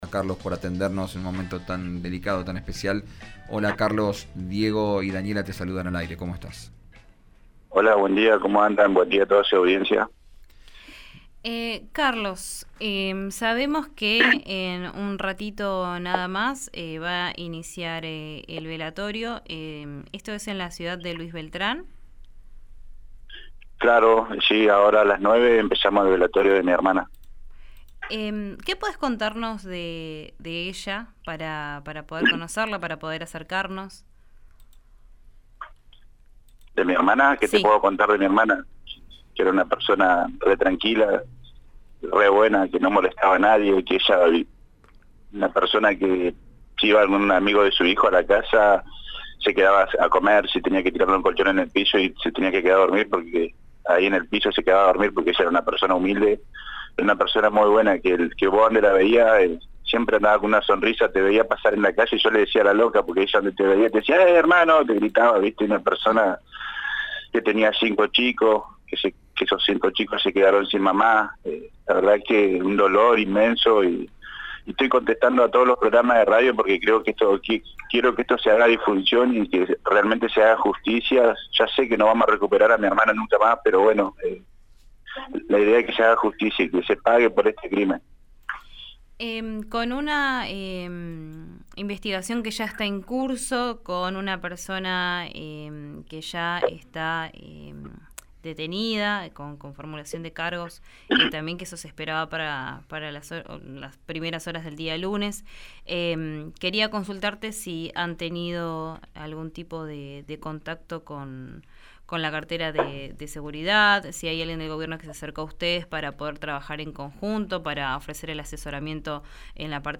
se expresó con profundo dolor y aún conmocionado por el suceso